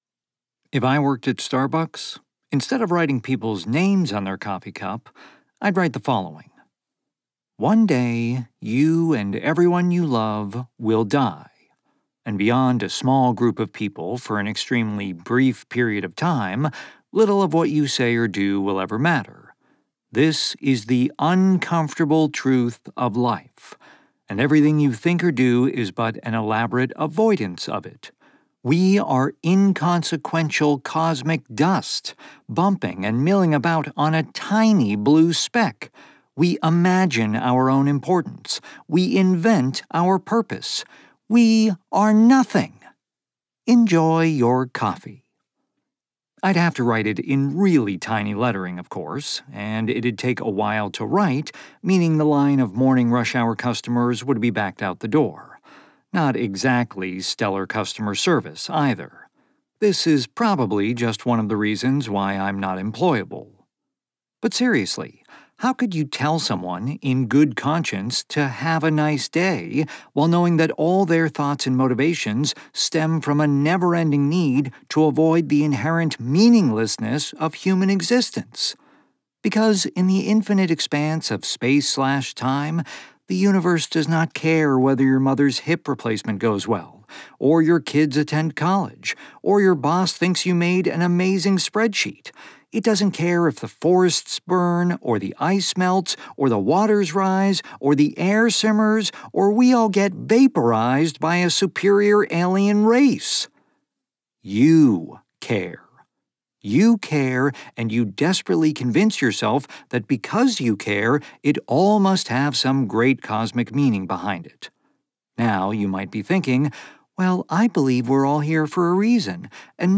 Non-Fiction: SELF-HELP (1st person POV, humor)
• Sennheiser MK4 microphone
• Home studio